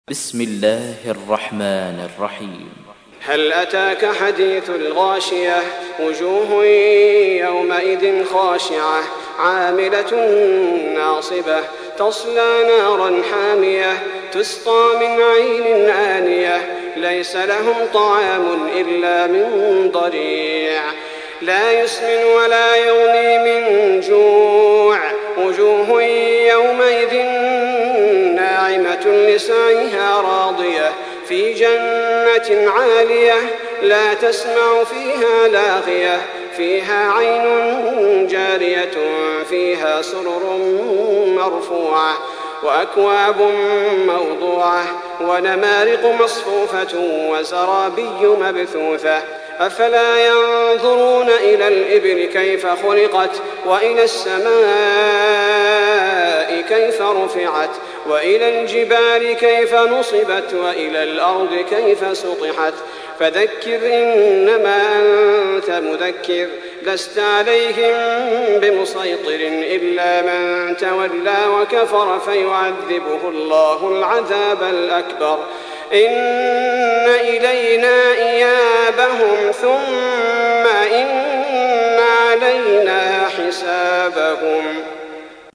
تحميل : 88. سورة الغاشية / القارئ صلاح البدير / القرآن الكريم / موقع يا حسين